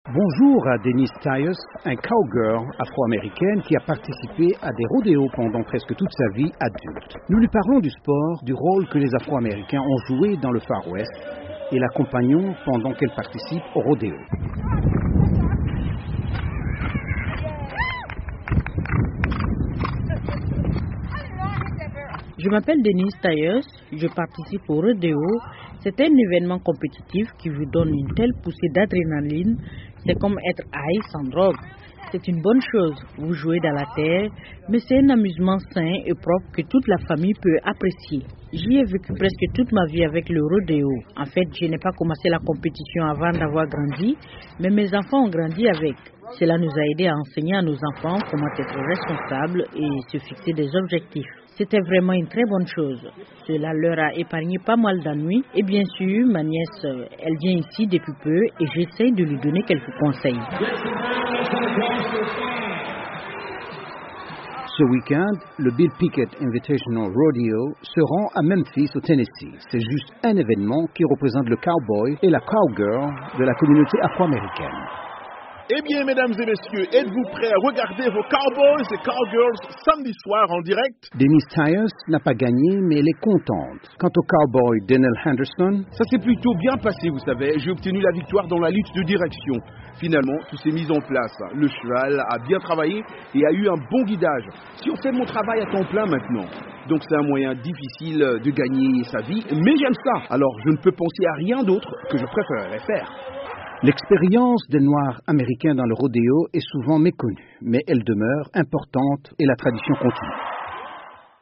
Reportage à Memphis dans le Tennessee